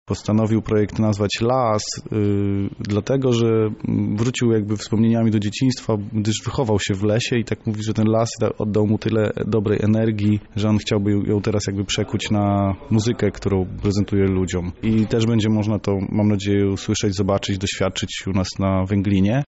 organizator koncertu